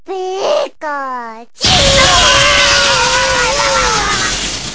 Pikachu Thunderbolt Sound Effect Free Download